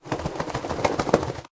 shake.ogg